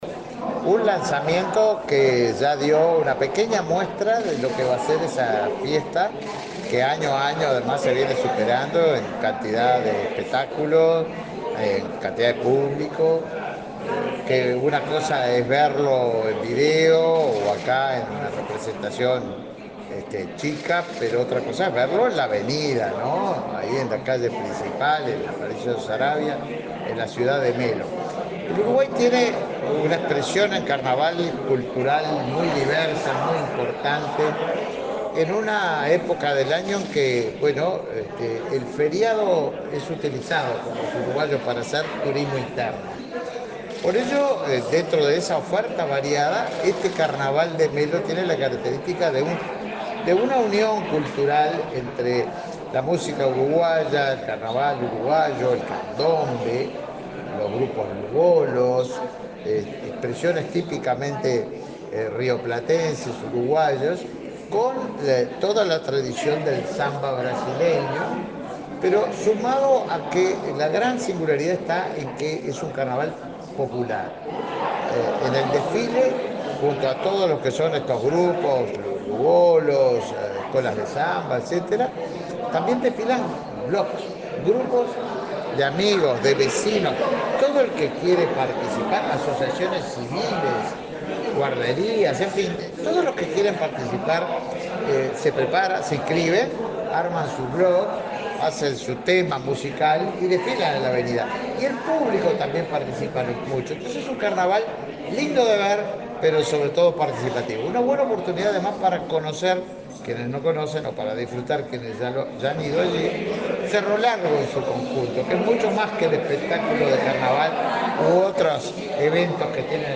Declaraciones del ministro de Turismo, Tabaré Viera
El ministro de Turismo, Tabaré Viera, dialogó con la prensa, acerca del lanzamiento del Carnaval de Melo, presentado este miércoles 13 en Montevideo.